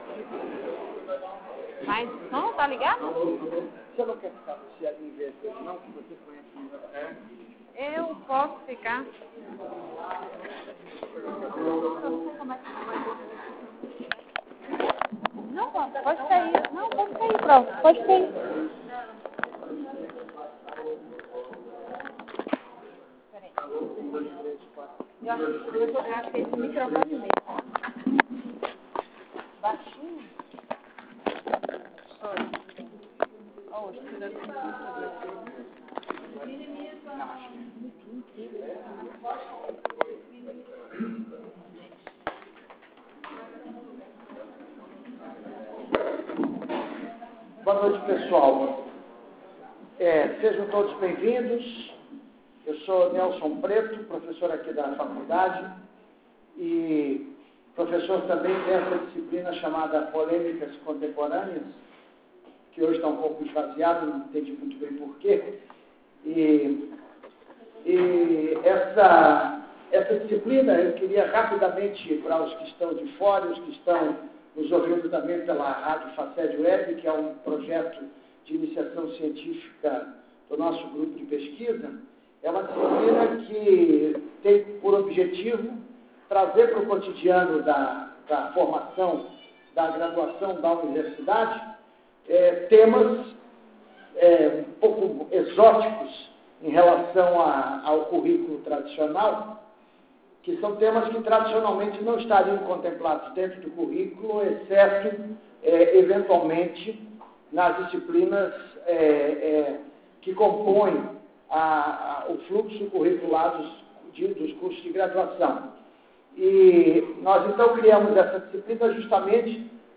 Aula de Pol�micas sobre a Banda LArga no Brasil.